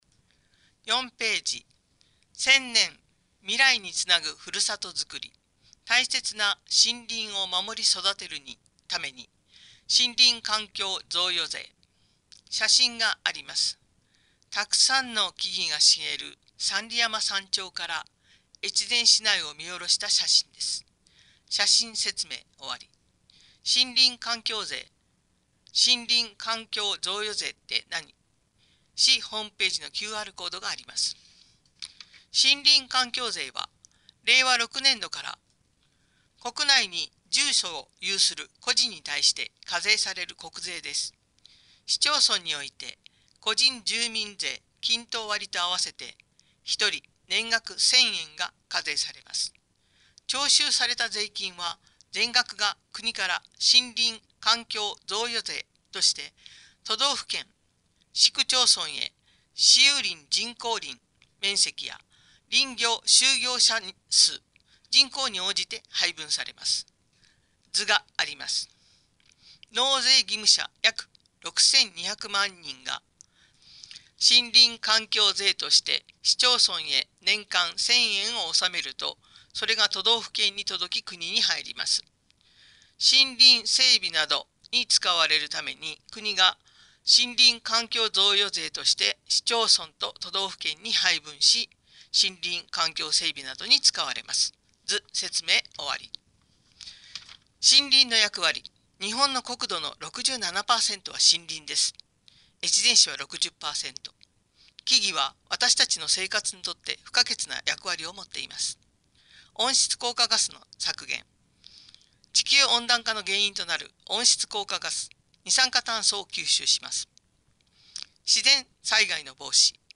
越前市広報５月号（音訳）